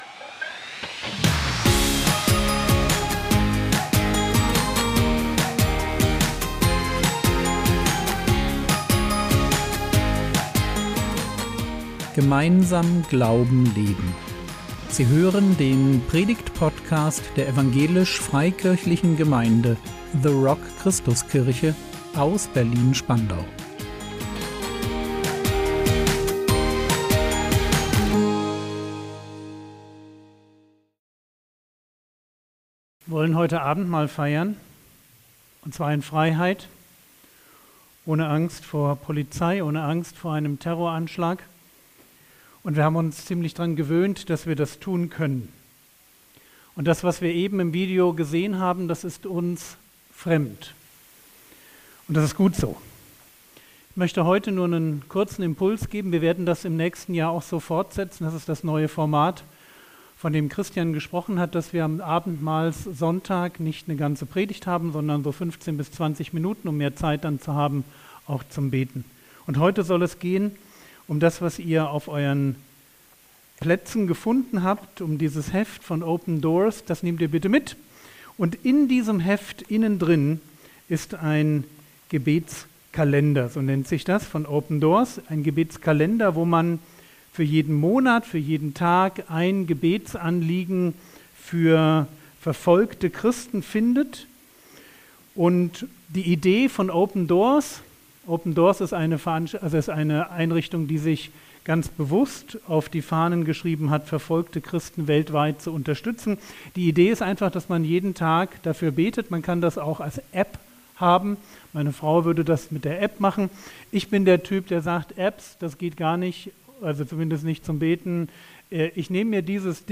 Gedenkt der Gefangenen | 08.12.2024 ~ Predigt Podcast der EFG The Rock Christuskirche Berlin Podcast